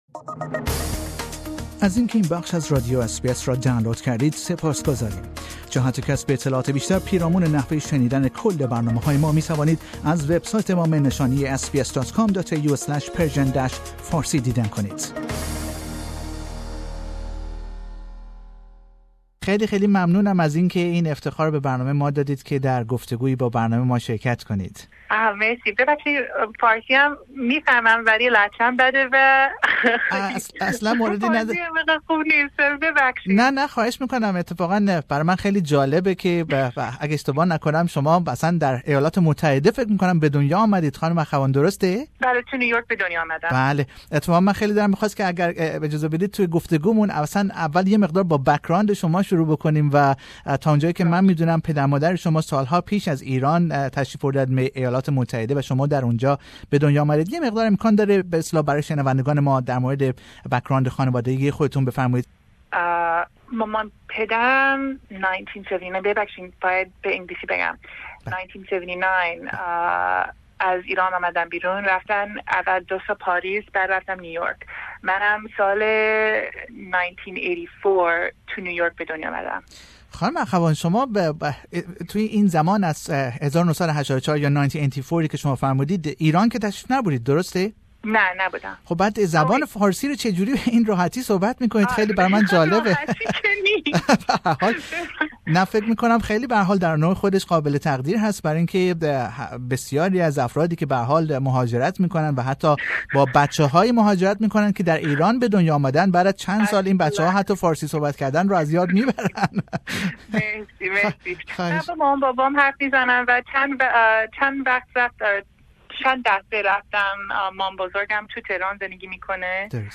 In an interview with SBS Persian Radio, Desiree Akhavan speaks about herself, her first feature film and future plans.